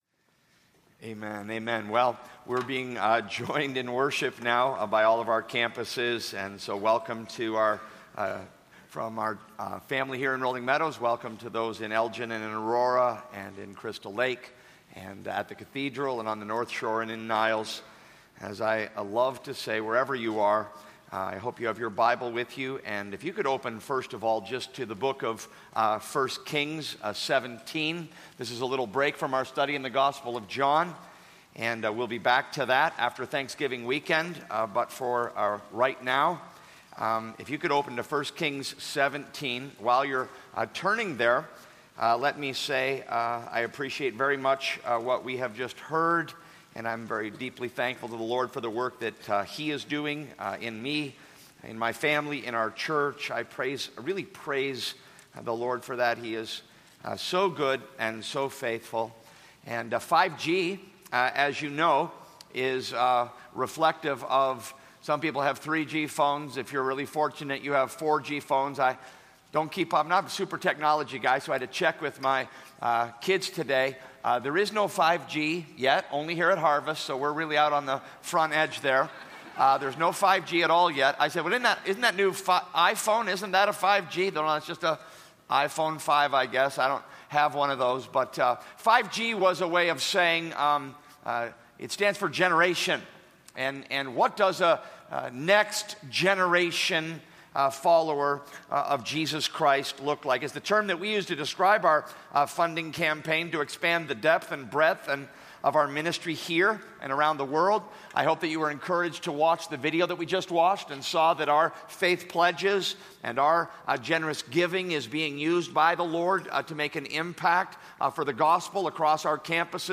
Please note that on November 18, 2013, MacDonald preached a message titled "My 5G" wherein he admitted to gambling both in private and in public, meaning casinos.